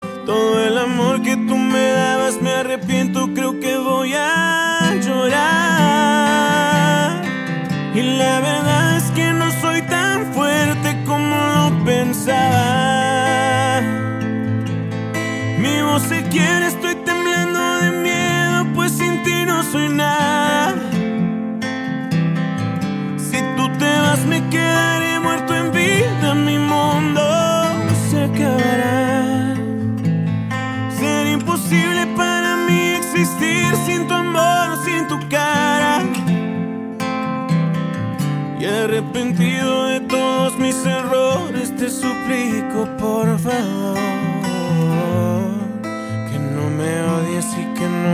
Classique